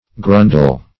Grundel \Grun"del\, n.